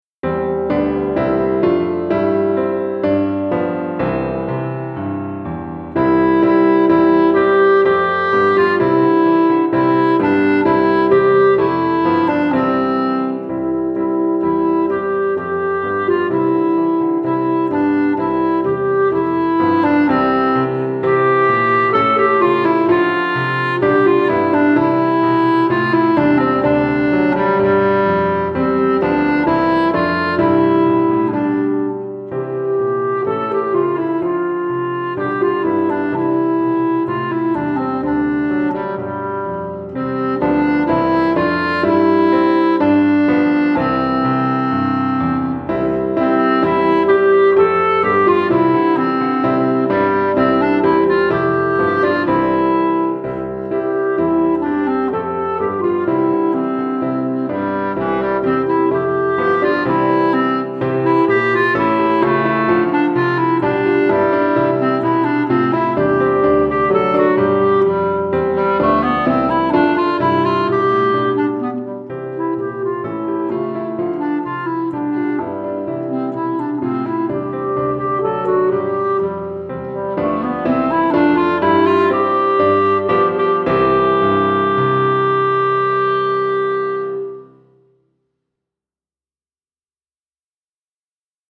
Clarinette et Piano